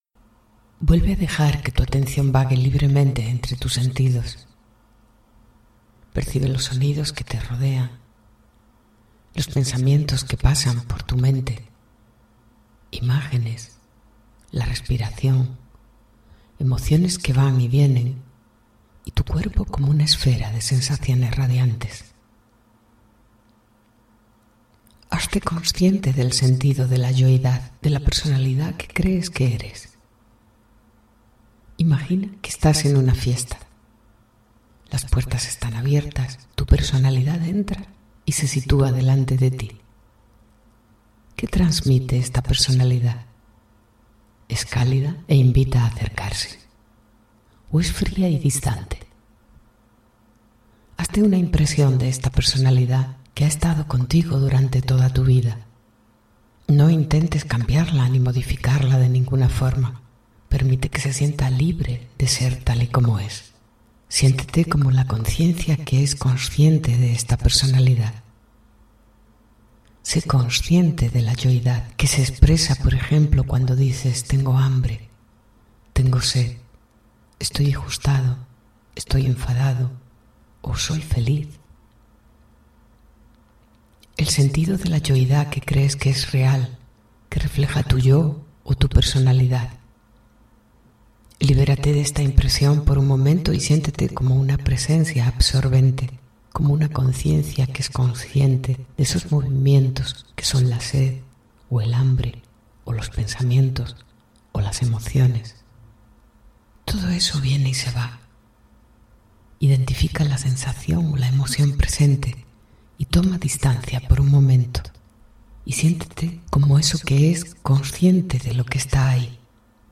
Yoga Nidra: Meditación Percibir la yoidad
5-YOGA_NIDRA_Percibir_la_yoidad.mp3